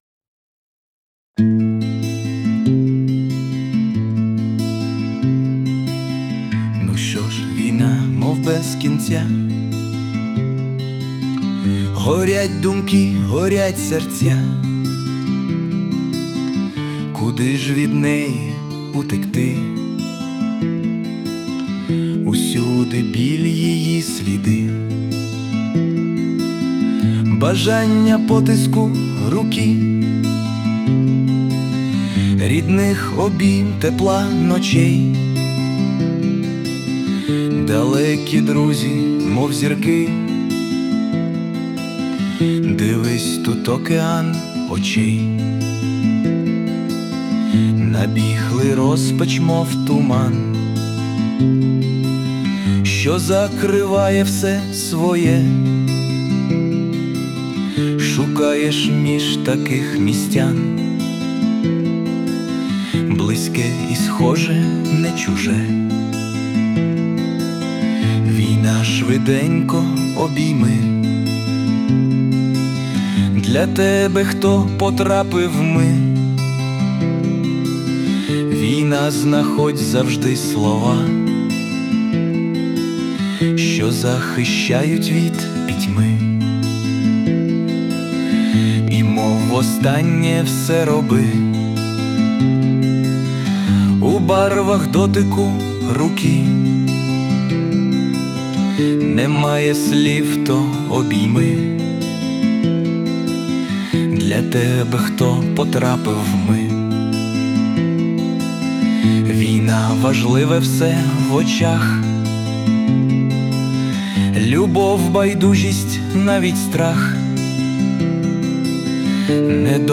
Власне виконання
СТИЛЬОВІ ЖАНРИ: Ліричний